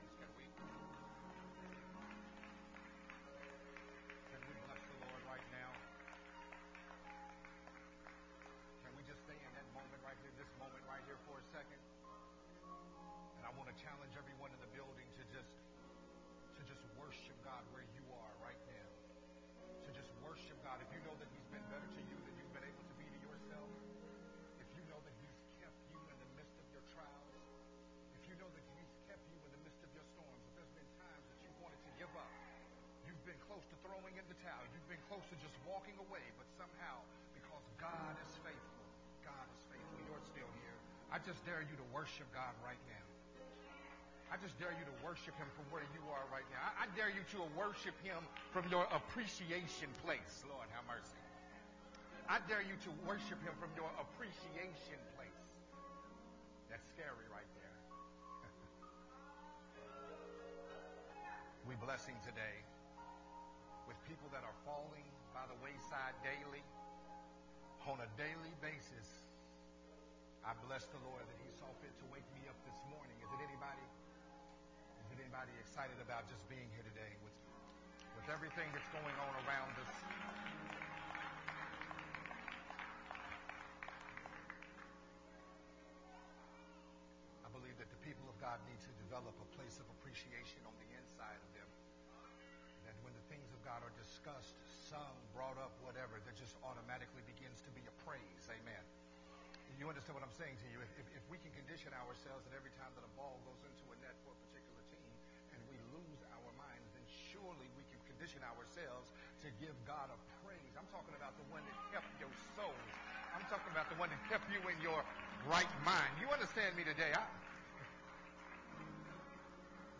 Part 1” part 6 of the sermon series “Walking in Wisdom”
recorded at Unity Worship Center on February 20,2022.